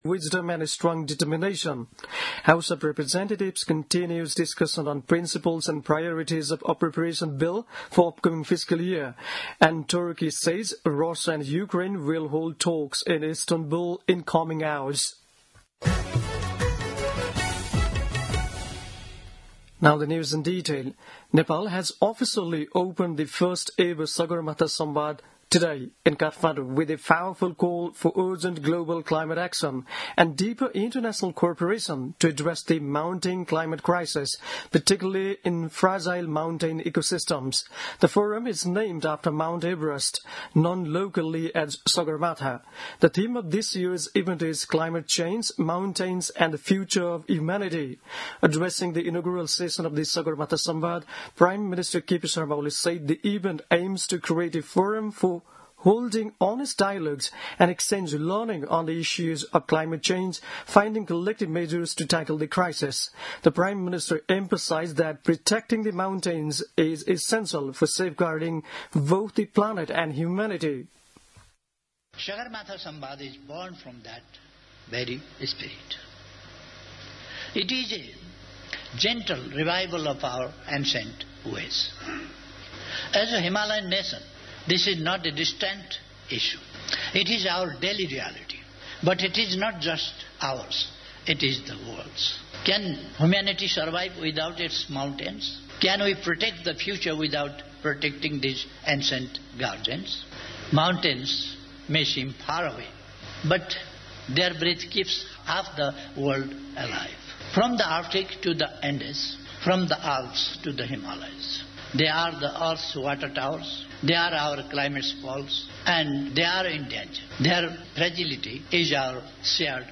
An online outlet of Nepal's national radio broadcaster
दिउँसो २ बजेको अङ्ग्रेजी समाचार : २ जेठ , २०८२